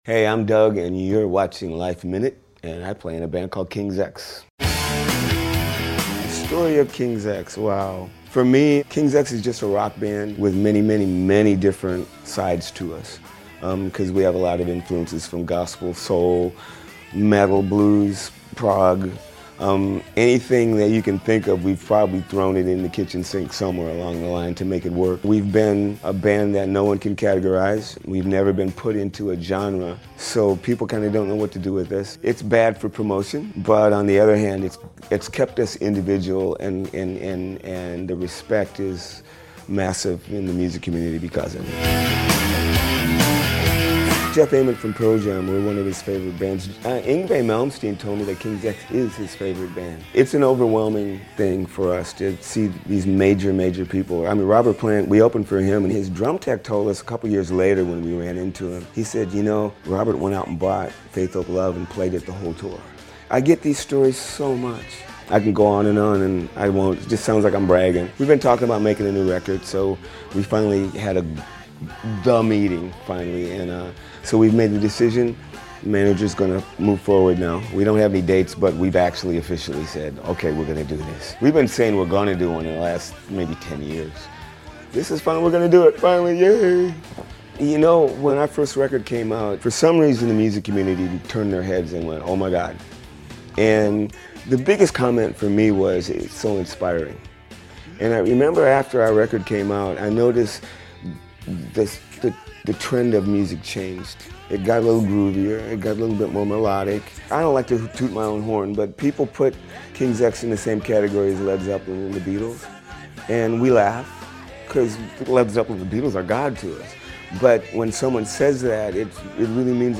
The three piece group injects progressive metal, rock, funk, soul, gospel, blues and after 10 years, frontman singer-bassist, Dug Pinnick told us they’ve just decided to get back into the studio for a new album, which will released next year. We caught up with him at the legendary Stone Pony in Asbury Park for some scoop.